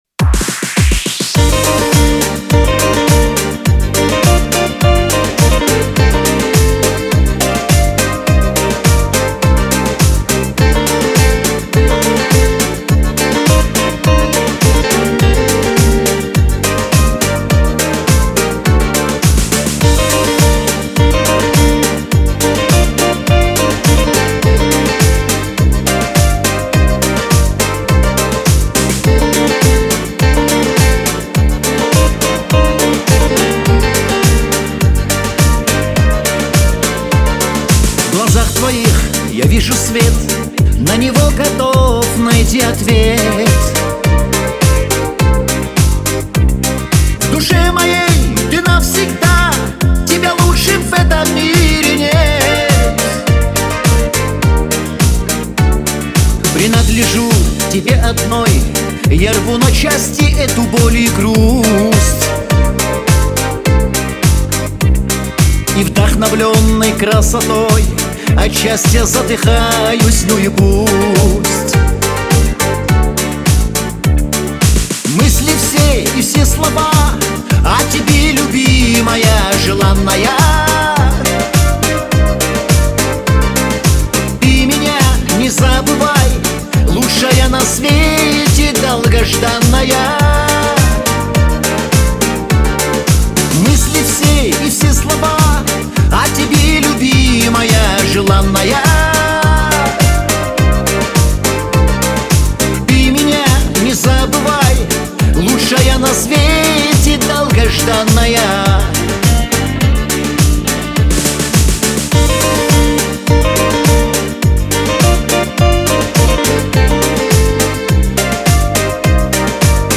в жанре поп-рок, наполненный светлыми эмоциями и надеждой.